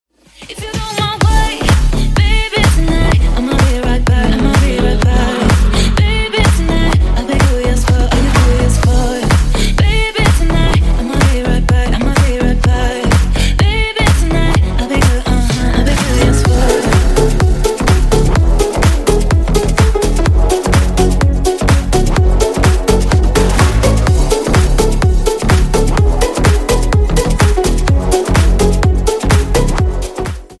# Клубные Рингтоны
# Танцевальные Рингтоны